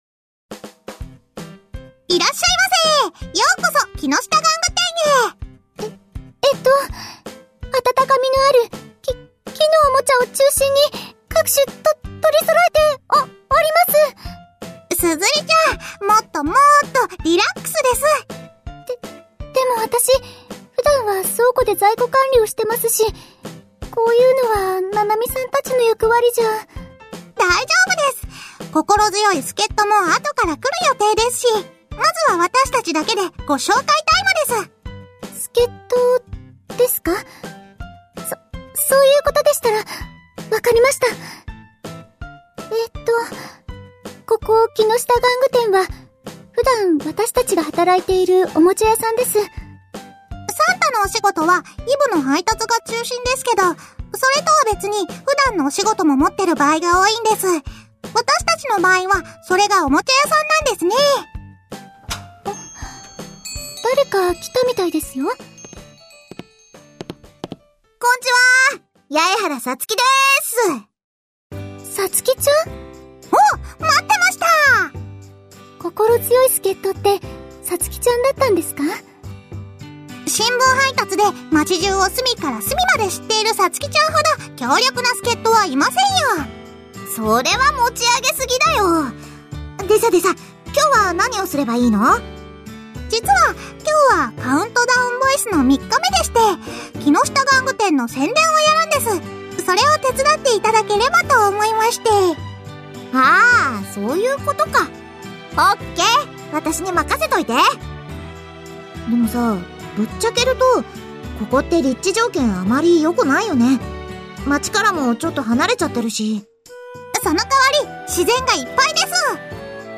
カウントダウンボイスが出そろったので、まとめてみた。